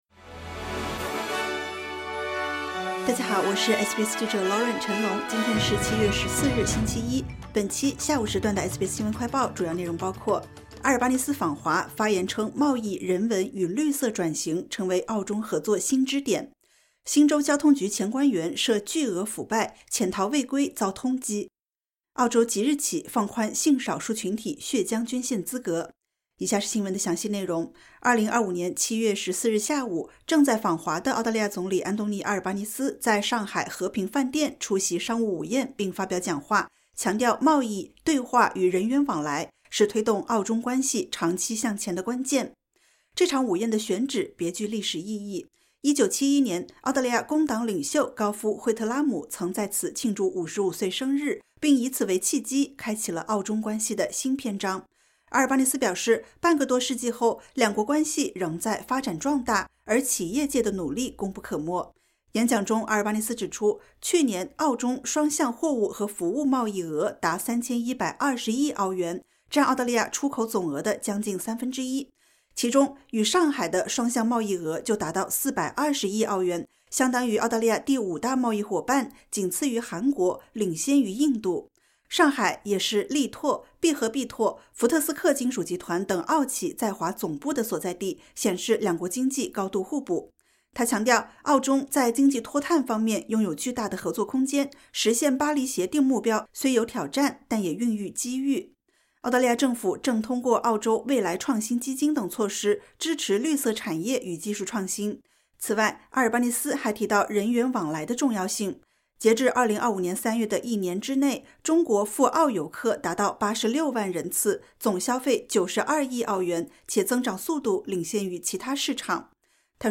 SBS 新闻快报